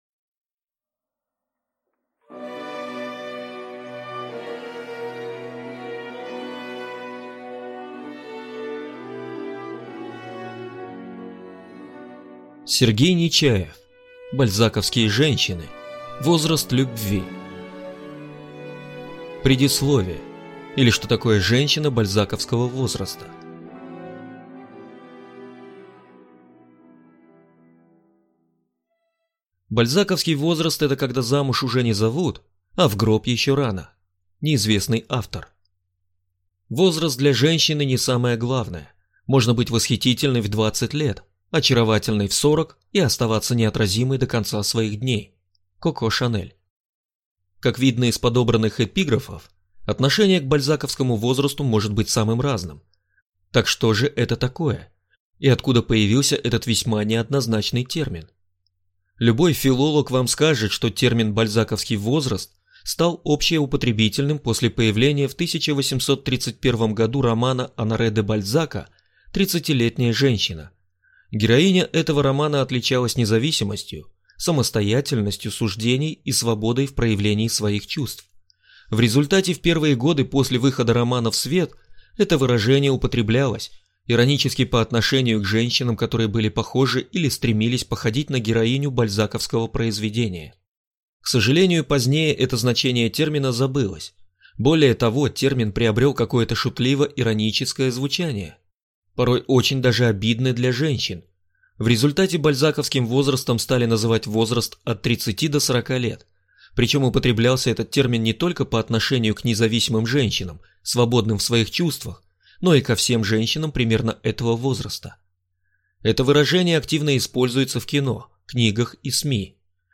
Аудиокнига Бальзаковские женщины. Возраст любви | Библиотека аудиокниг